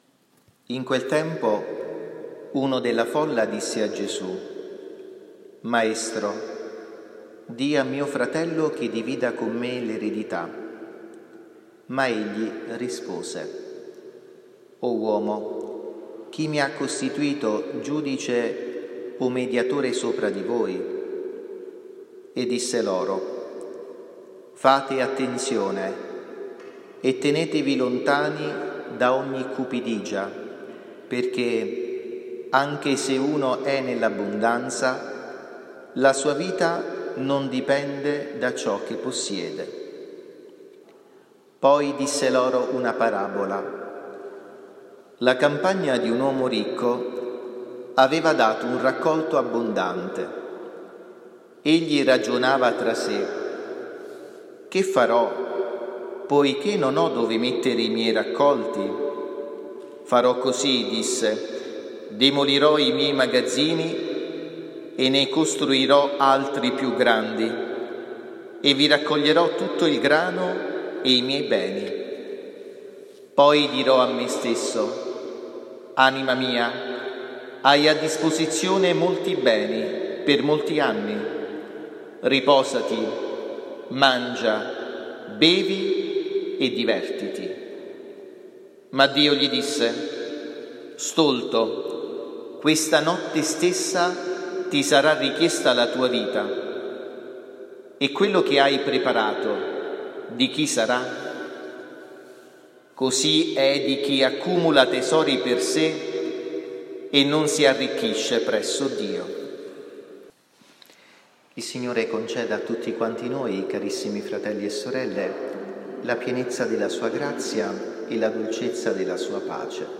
XVIII DOMENICA DEL TEMPO ORDINARIO (ANNO C) – 31 luglio 2022
omelia-31-luglio-22.mp3